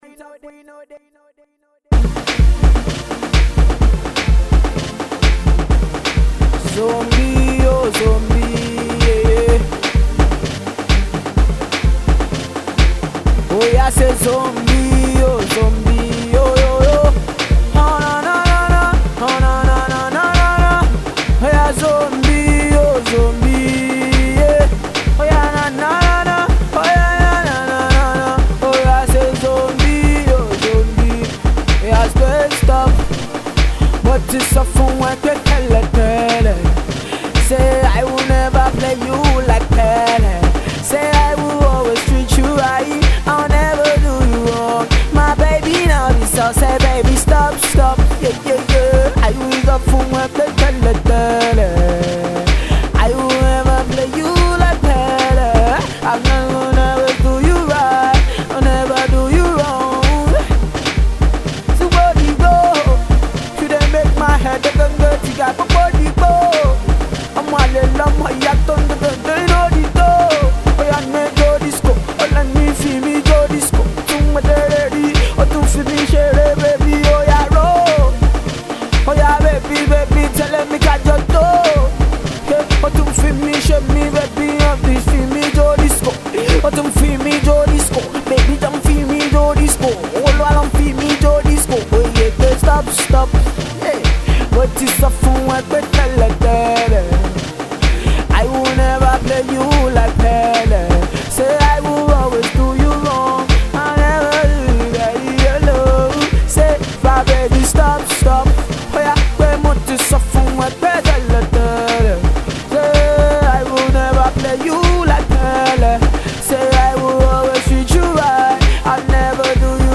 freestyle track